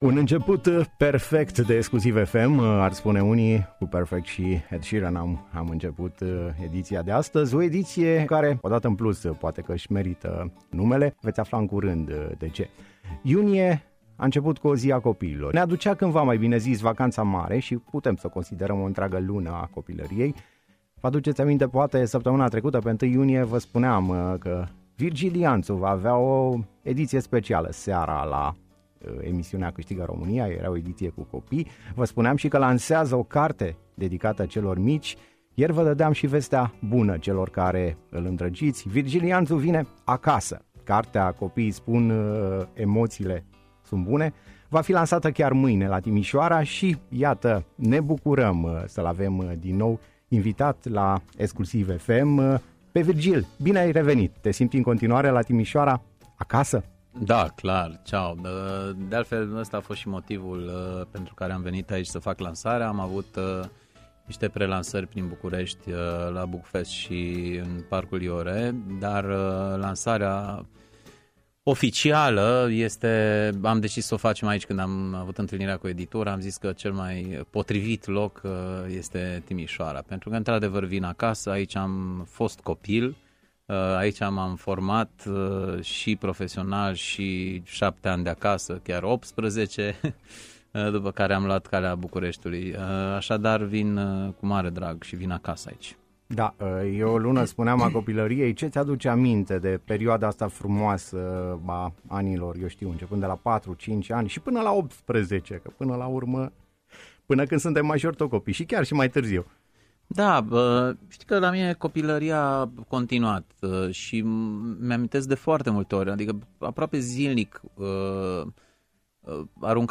?Virgil Ianțu, în direct la Exclusiv FM, despre prima lui carte pentru copii - Radio România Timișoara